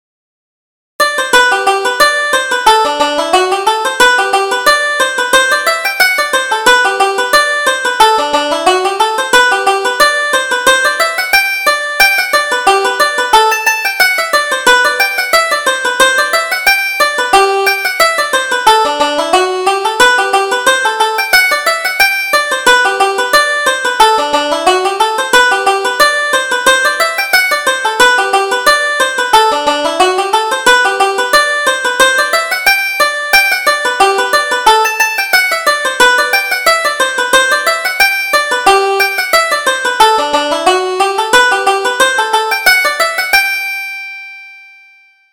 Reel: The Ladies of Leinster